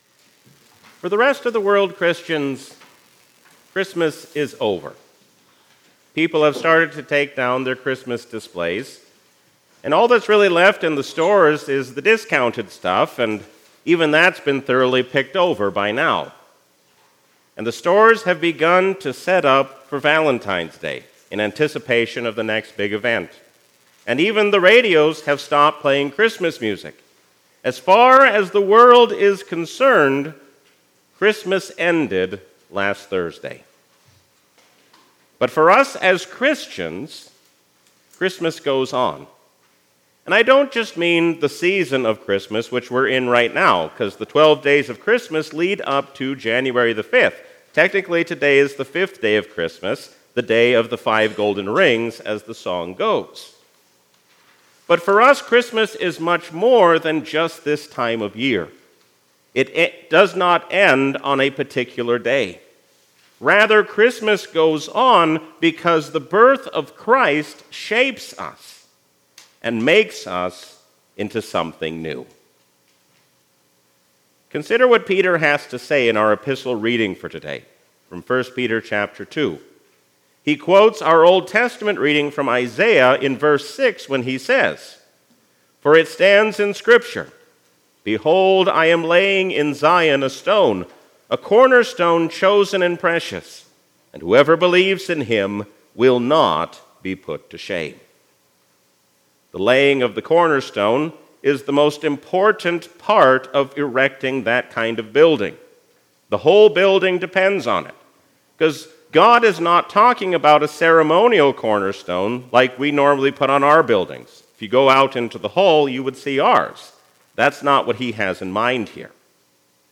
A sermon from the season "Christmas 2024." Jesus is the reason for the season because He has come to be our Brother and our Savior.